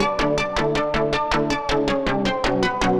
Index of /musicradar/future-rave-samples/160bpm